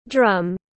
Drum /drʌm/